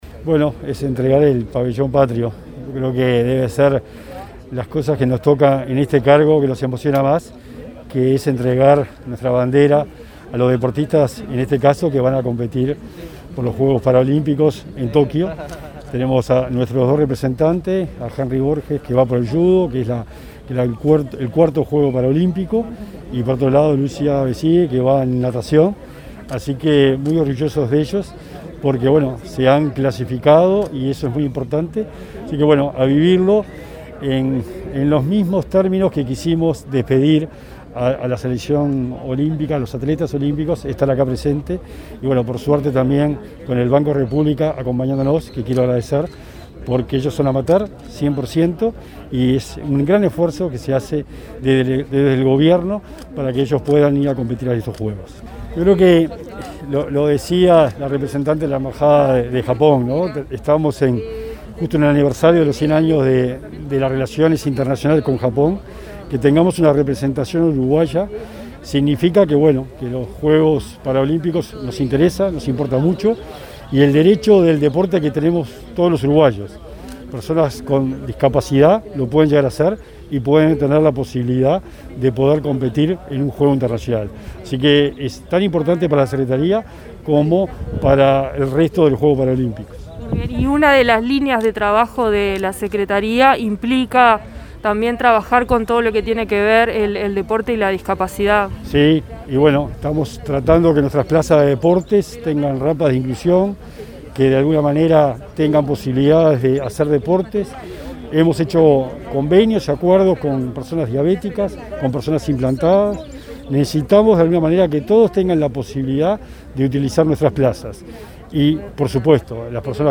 Entrevista al secretario nacional del Deporte, Sebastián Bauzá
En diálogo con Comunicación Presidencial, este jueves 5, en la pista de atletismo Darwin Piñeyrúa, el secretario nacional del Deporte, Sebastián Bauzá